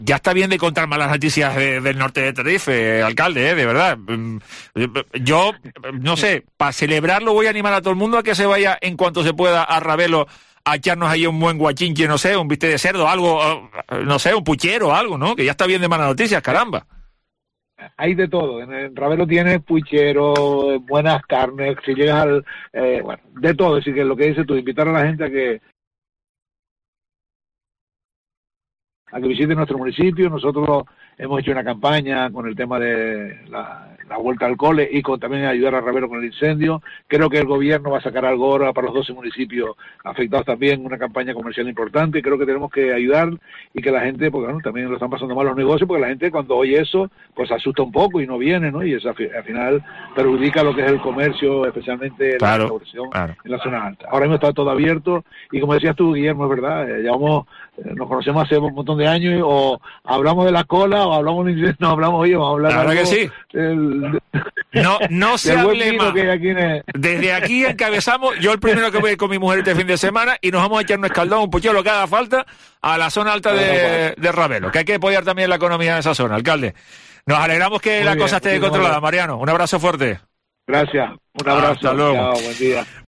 Mariano Pérez, alcalde de El Sauzal, analiza la reactivación del incendio en su municipio
En este contexto, el alcalde de El Sauzal, Mariano Pérez, ha sido muy claro hoy en Herrera en COPE Tenerife, ya que la zona de Ravelo, conocida especialmente por este tipo de establecimientos, ha sido una de las afectadas.